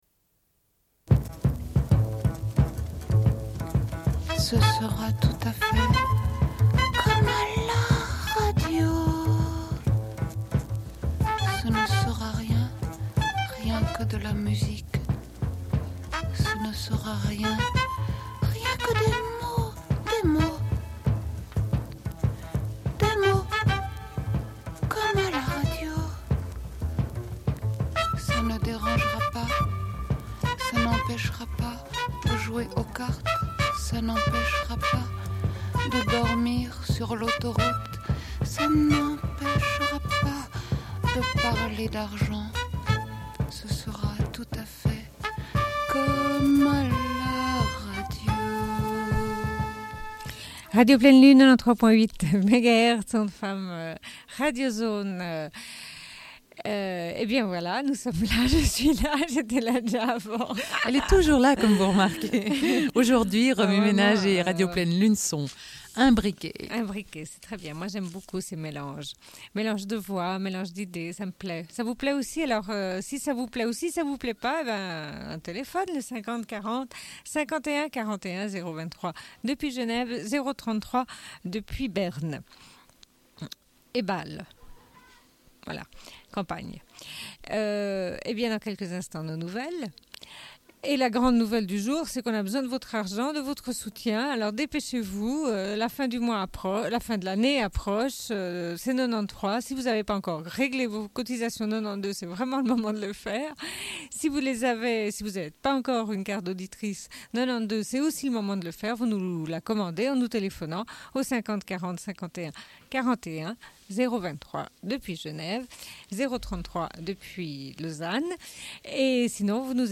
Une cassette audio, face A31:52